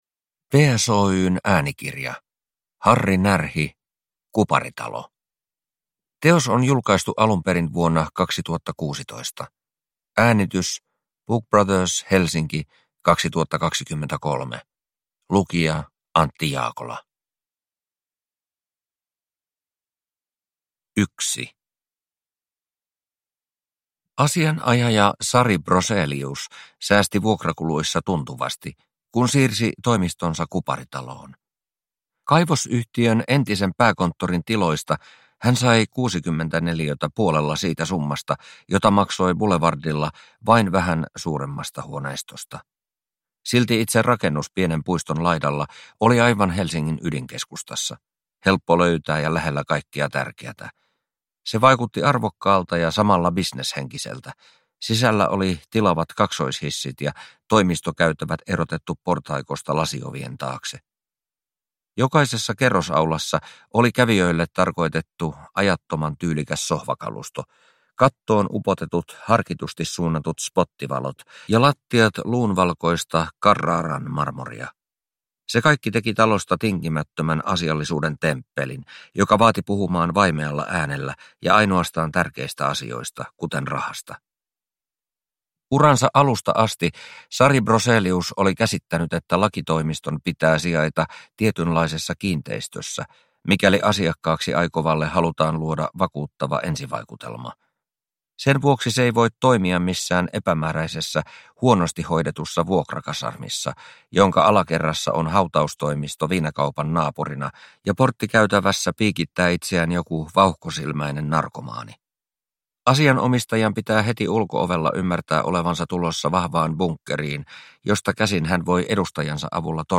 Kuparitalo – Ljudbok – Laddas ner